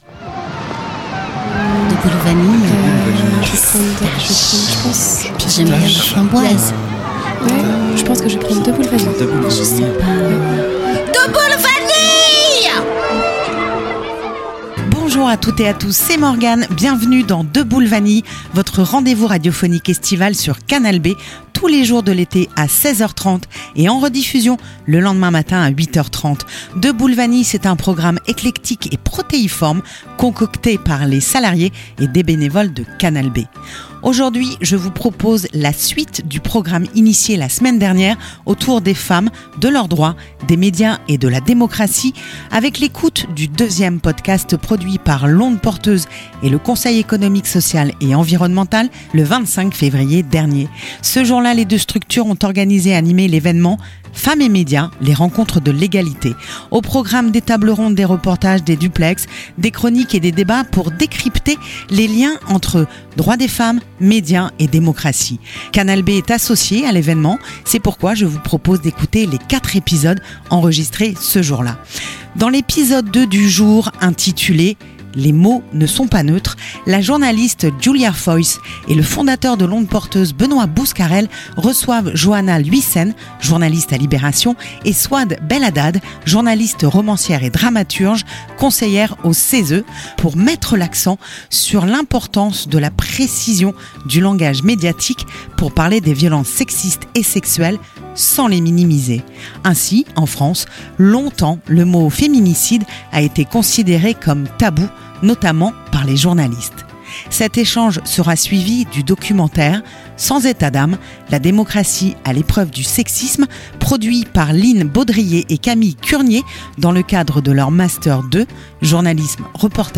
Cette journée d’échanges était organisée en partenariat avec Equipop, Ouest-France, Reporters sans frontières, Prenons la Une, l’Agence la Fronde et L’Onde Porteuse donc Canal B est associée à l’événement